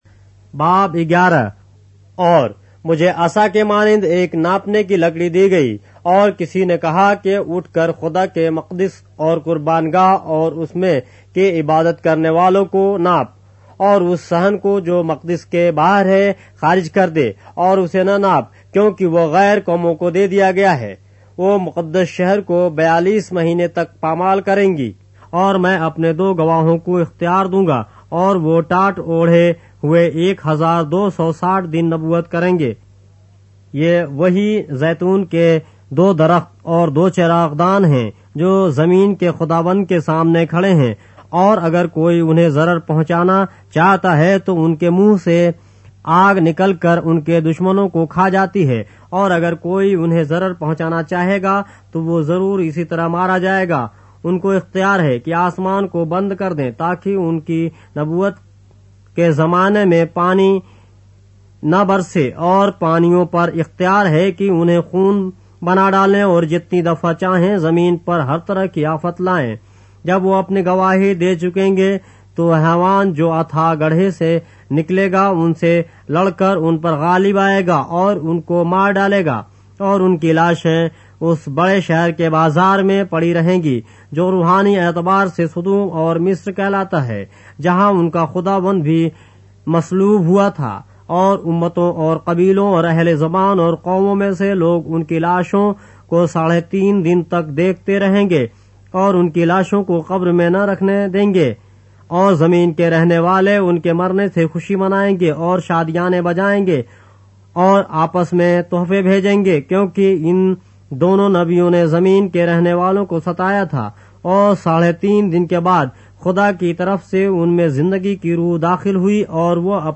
اردو بائبل کے باب - آڈیو روایت کے ساتھ - Revelation, chapter 11 of the Holy Bible in Urdu